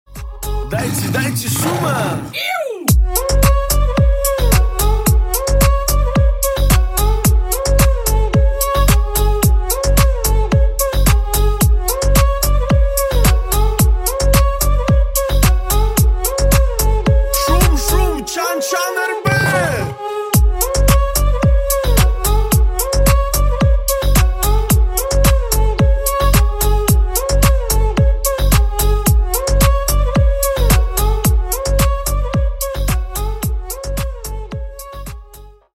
2023 » Новинки » Клубные » Русские » Поп Скачать припев